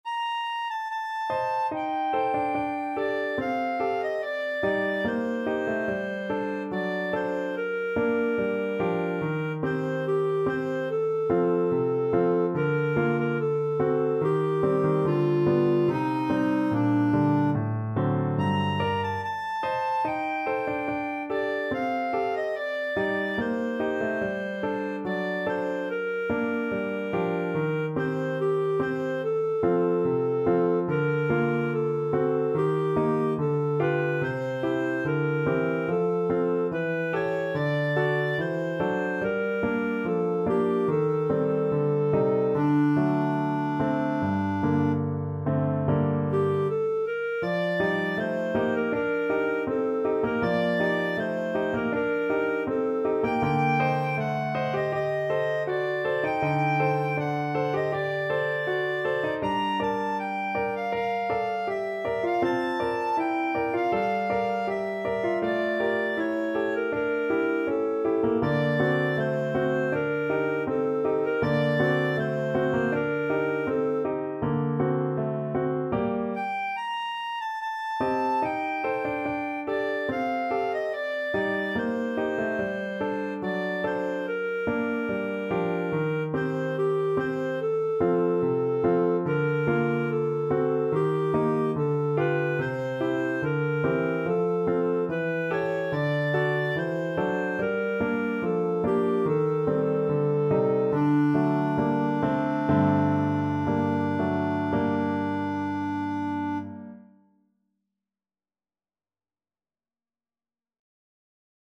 ~ = 72 Andantino (View more music marked Andantino)
2/4 (View more 2/4 Music)
Classical (View more Classical Clarinet Music)